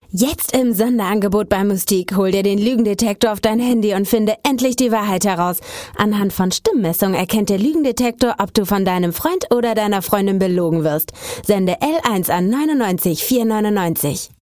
Sprecherin, Werbesprecherin, Stationvoice